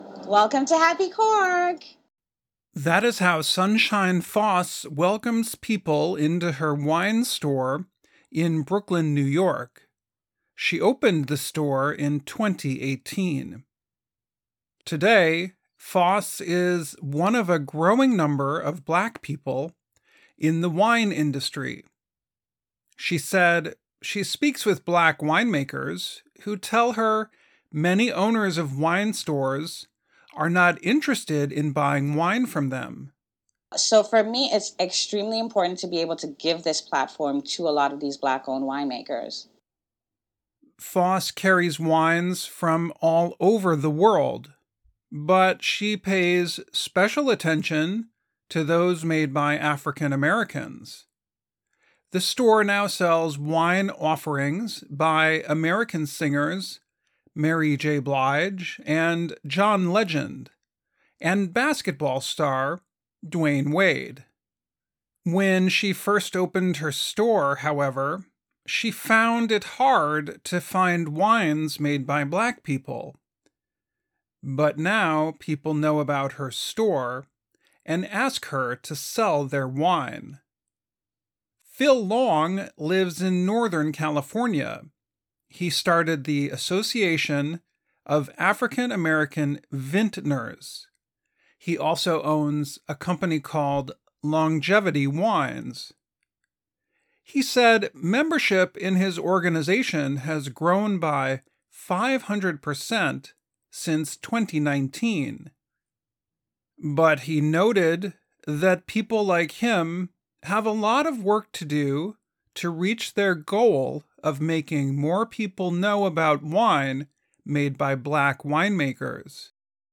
慢速英语:美国黑人酿酒师希望增加业务的多样性